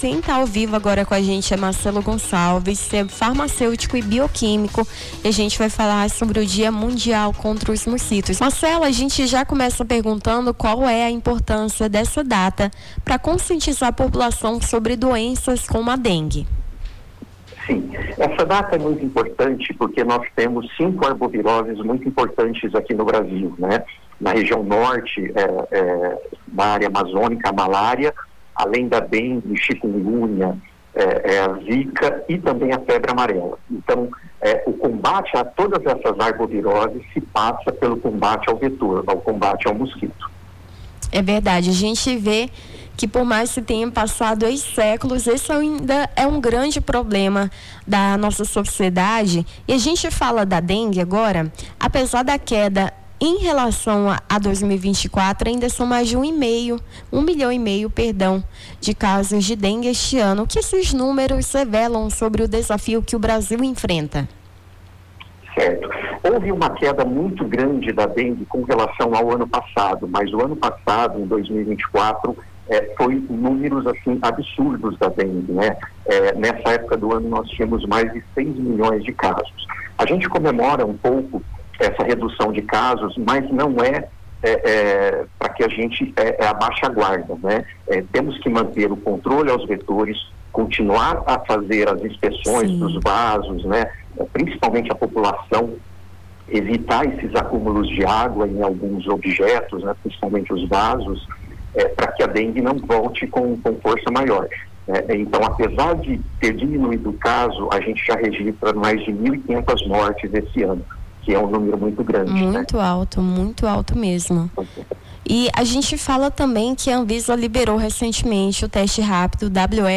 Nome do Artista - CENSURA - ENTREVISTA (DIA MUNDIAL CONTRA OS MOSQUITOS) 19-08-25.mp3